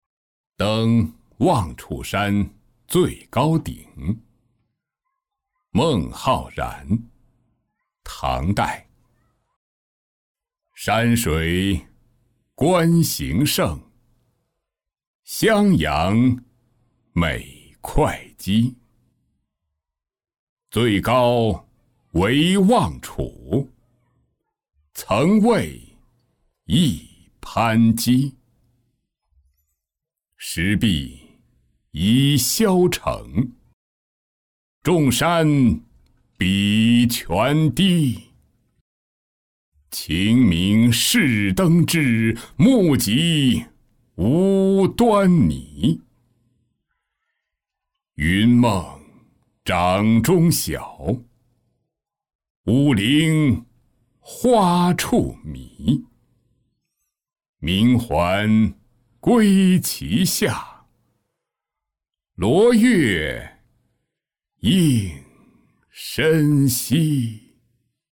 登望楚山最高顶-音频朗读